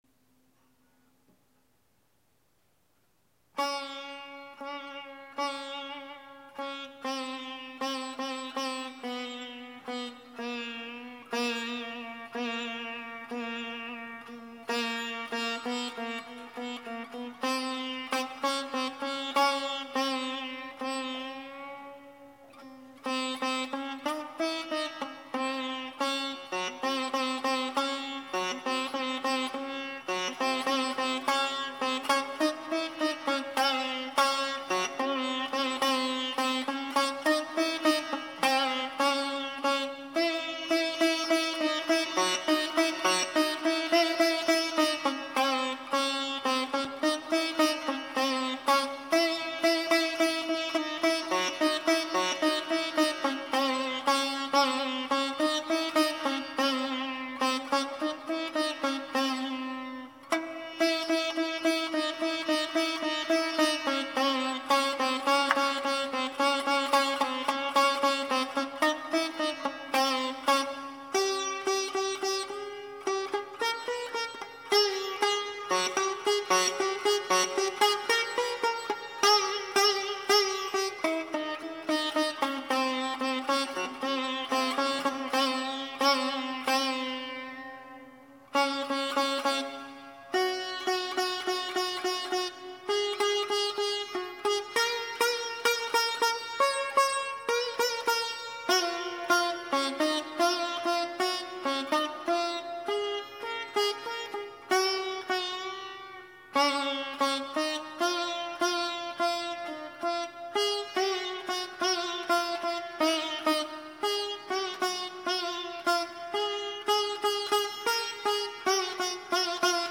Esraj